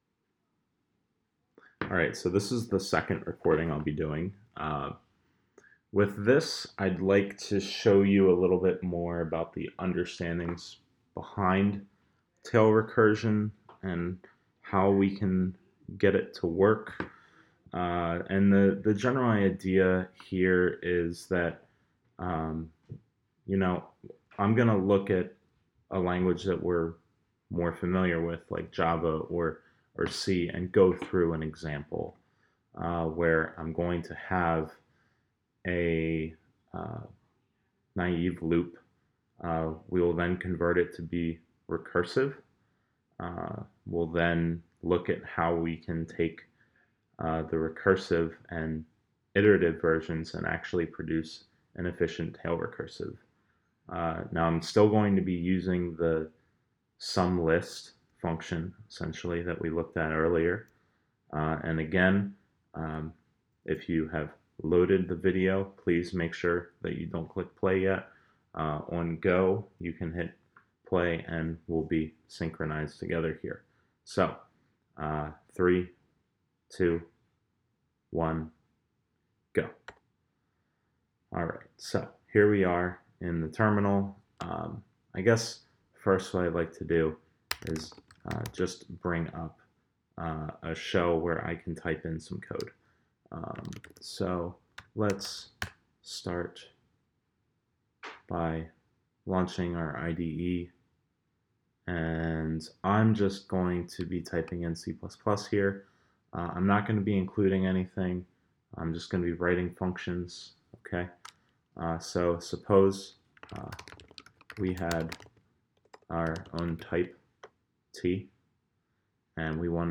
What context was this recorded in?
I indicate in the audio recording when you should start the screen cast.